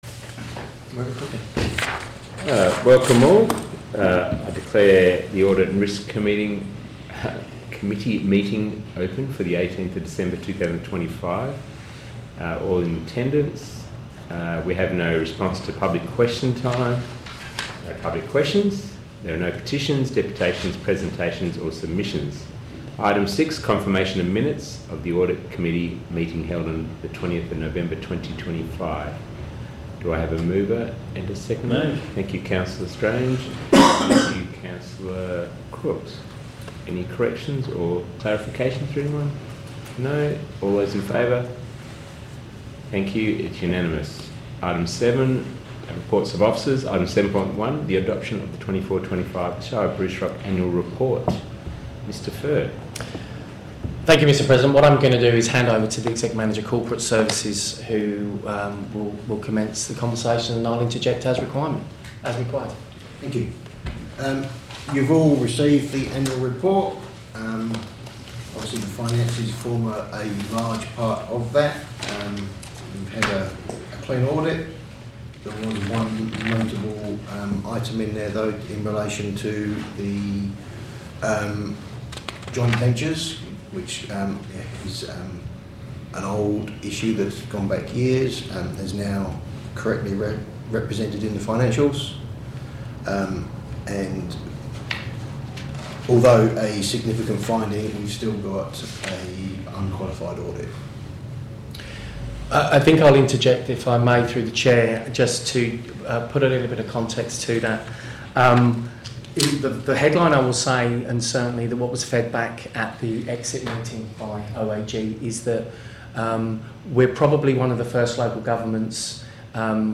Audit and Risk Committee Meeting December 2025 » Shire of Bruce Rock